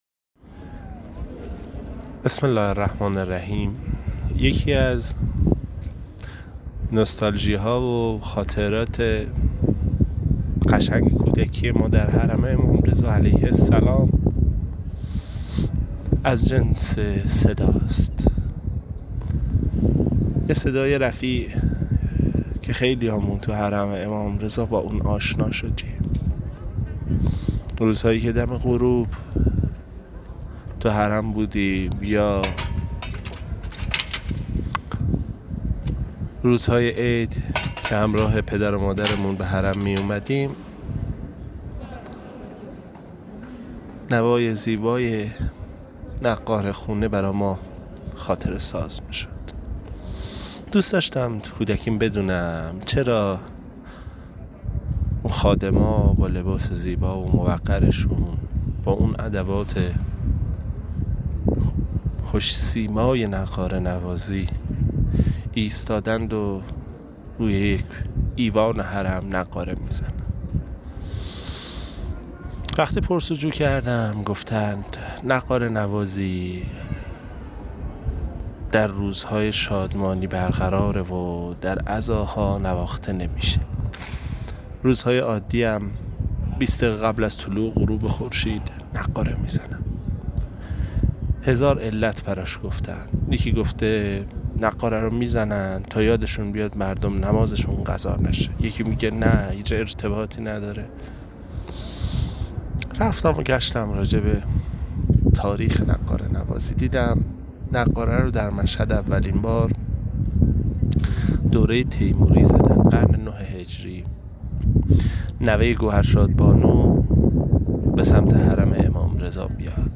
نقاره خانه
نقاره‌ زنی از آیین‌های کهنی است که در حرم مطهر امام رضا علیه السلام اجرا می‌شود؛ نمادی مورد احترام با طرفداران بسیار که نشان از عظمت، شکوه و شادمانی دارد.